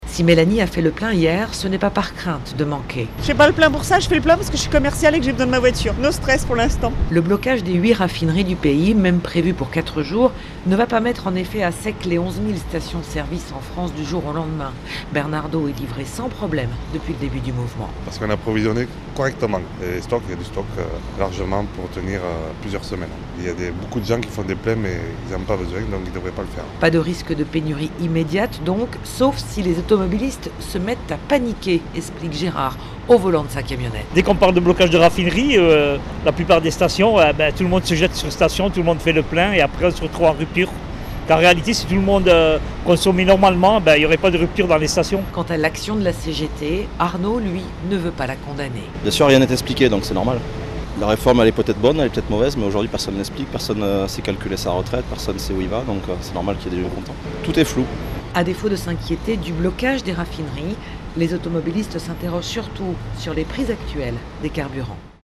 Pourtant dans les stations, les automobilistes attendent de voir l'évolution de la situation.